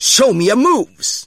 Captain Falcon Show Me Your Moves! Sound Effect.